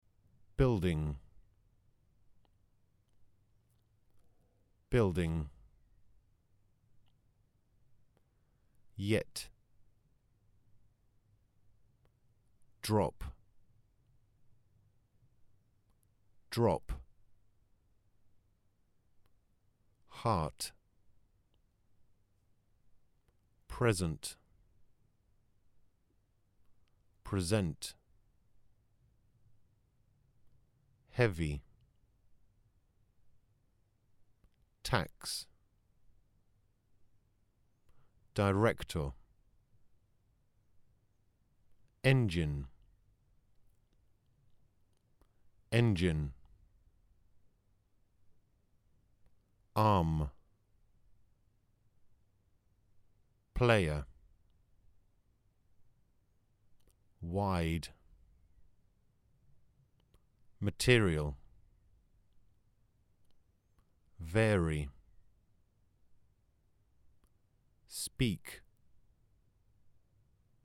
I already made some recordings with ZOOM H4, 96kHz, WAW. The recording consists of separate words, which will be cropped and stored as separate samples to use in a...
I attach a sample of the recording which wasn’t processed anyhow or changed – apart from saving it to MP3 because of the large file size of WAWs.
As you can hear in the sample the word DROP has the explotion ( it happens when P is at the ends).